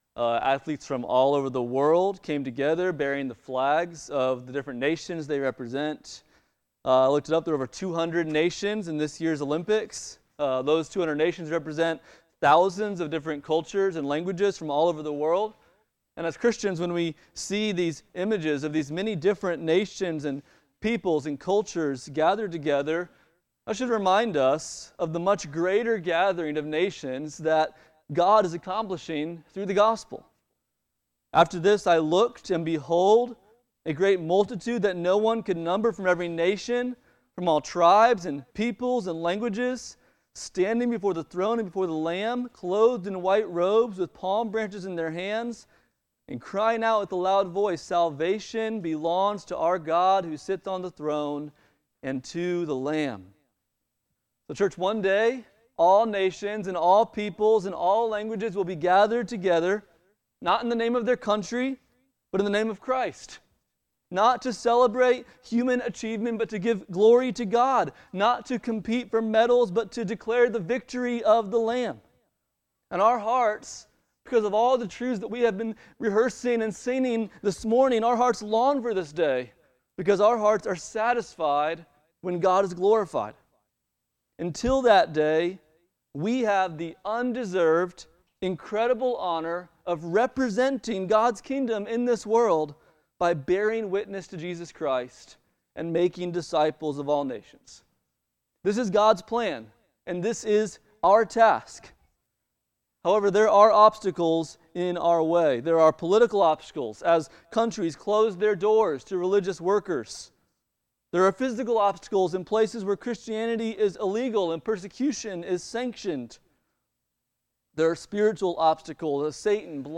Passage: Acts 8:1-25 Service Type: Sunday Morning